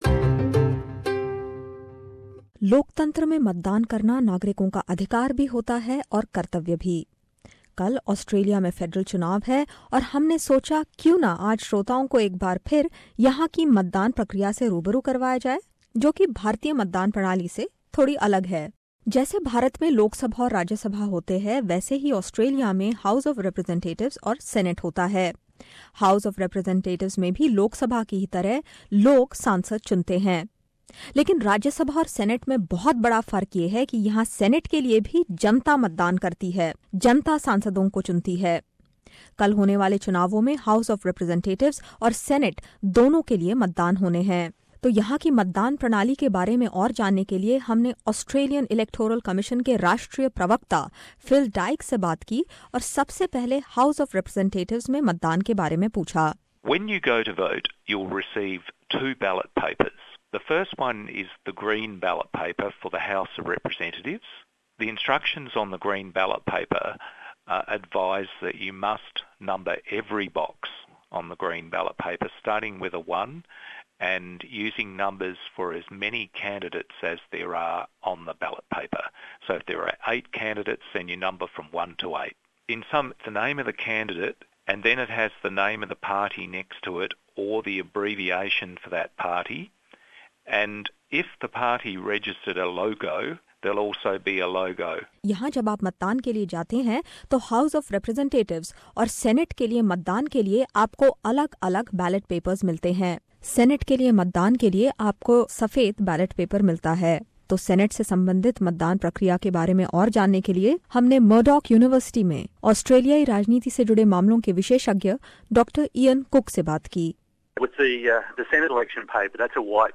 Is the Aussie voting system appearing too overwhelming for you? Then do tune into this report where we attempt to simply things for you.